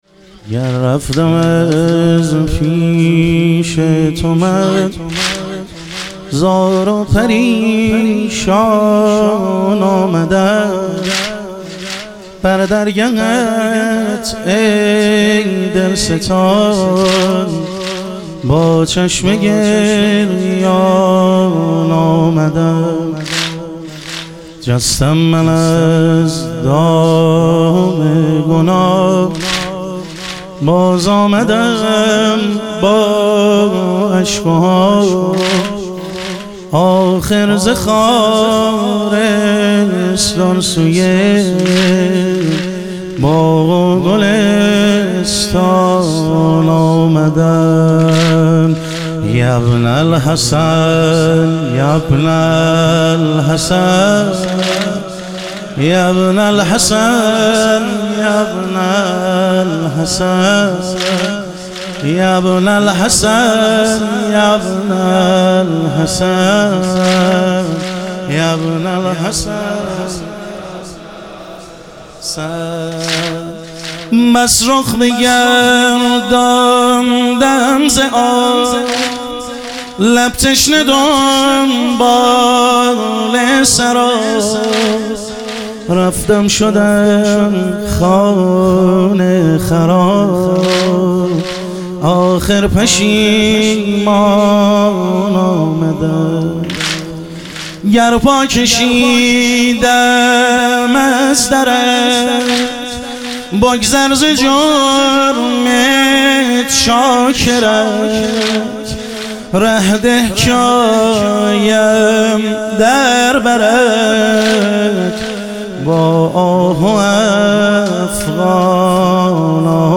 شهادت حضرت سلطانعلی علیه السلام - واحد